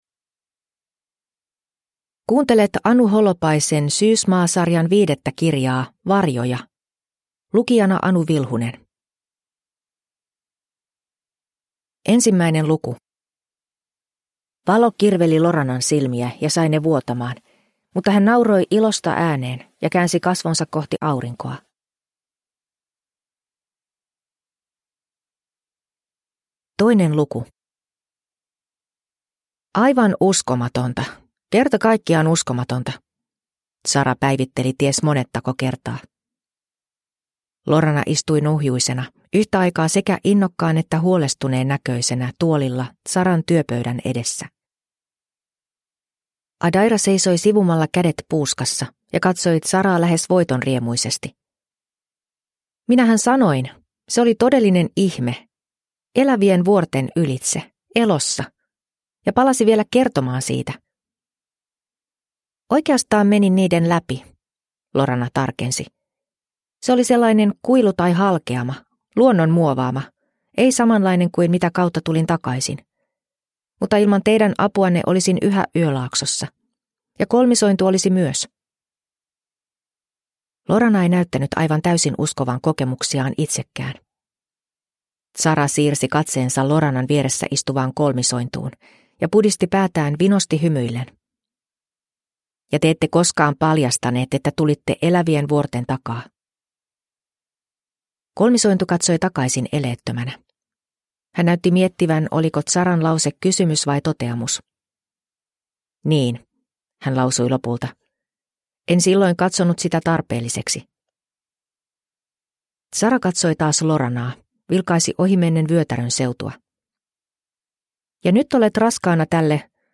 Varjoja – Ljudbok